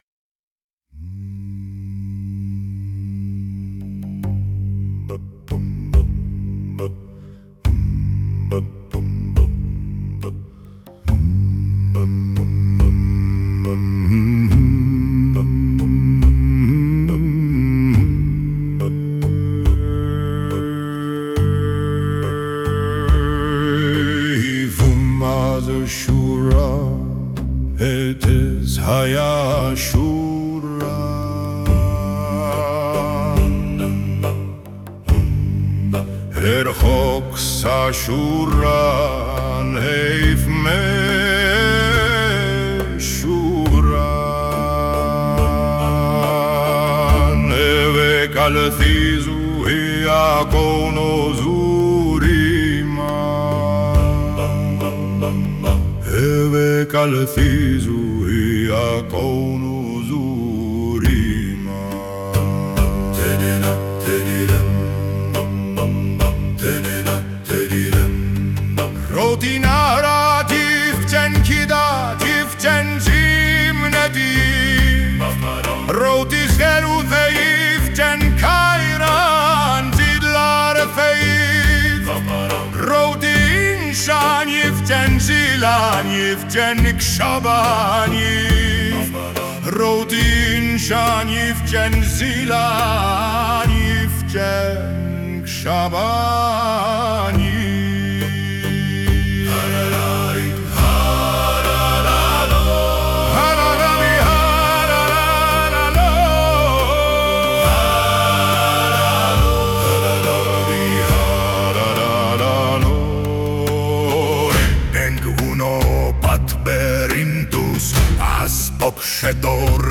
Autor deklaruje, że utworzył tę piosenkę przy pomocy Suno AI i zgodnie z regulaminem tego serwisu, posiada prawo do wykorzystania i upowszechniania tego pliku. Utwór wykonany został pod częściową inspiracją sardyńskim Cantu a Tenore, a także chórami gruzińskimi i bizantyjskimi.